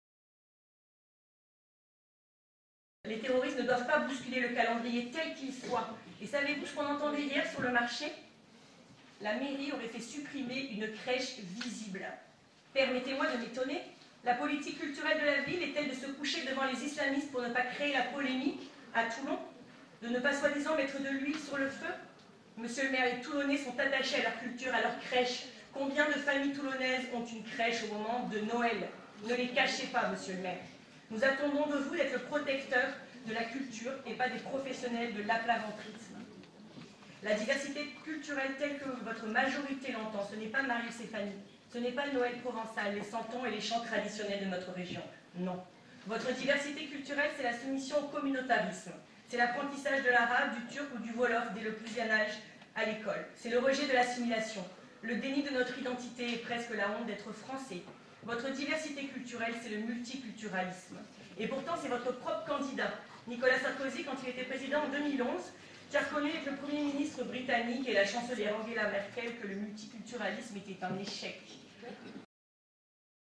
En fin de semaine, la question restait ouverte, même si elle n’a pas manqué d’être abordée jeudi dernier lors du dernier conseil municipal, au cours duquel Laure Lavalette, conseillère d’opposition, a vivement interpellé la majorité municipale (